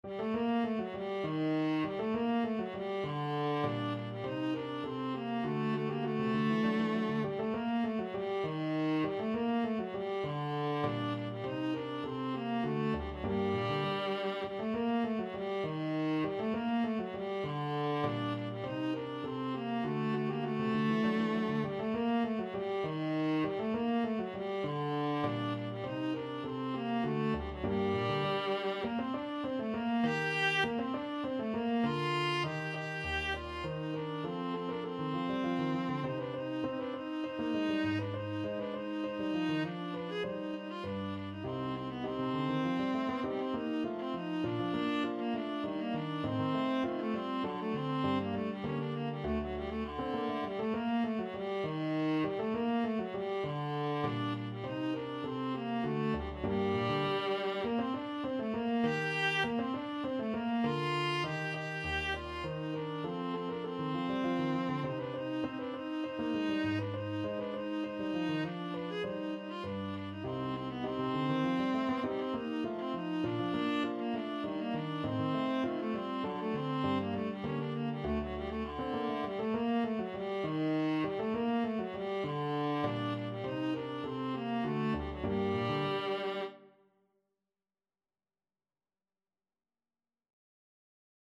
Viola
3/4 (View more 3/4 Music)
G minor (Sounding Pitch) (View more G minor Music for Viola )
Allegretto = 100
Classical (View more Classical Viola Music)